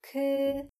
I do have a short audio file, the audio file is of someone reading the letters “ke”, however around 2/3 of the audio file you can hear a disturbing background noise that I don’t seem to be able to remove using Audacity. Maybe you have to put your volume a bit higher to hear annoying background noise.
I hear what sounds like a door slamming in the background.